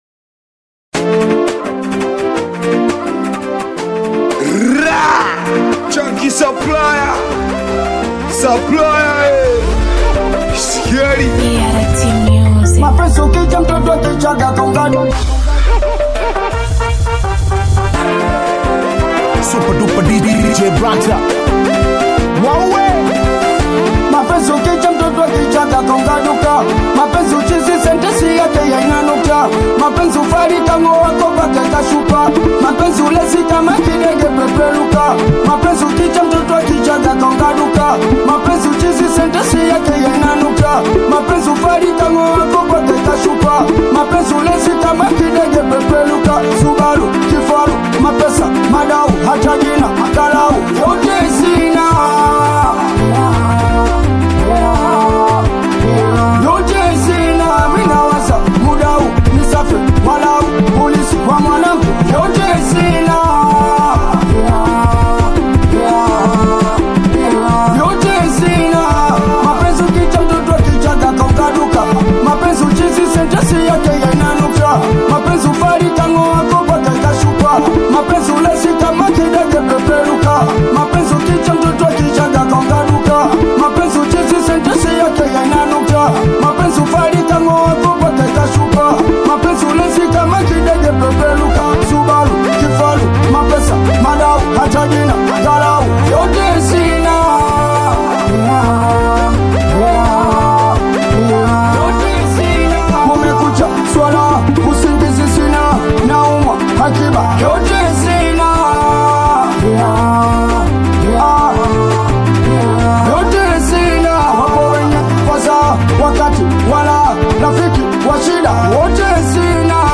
AUDIO BITI SINGELI SINGELI